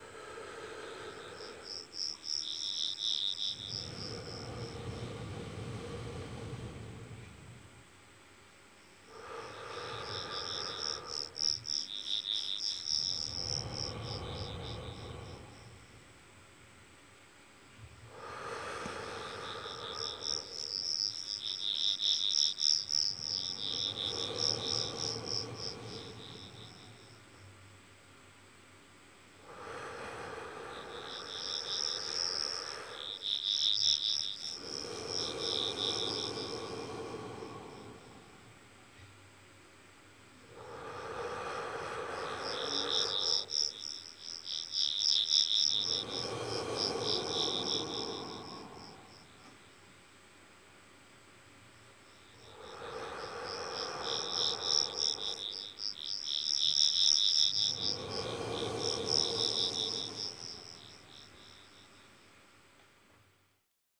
This interactive sound installation is inspired by the notion of breathing with the forest, and aims to emulate that feeling through a breath controlled synthetic sound mass reminiscent of a forest fu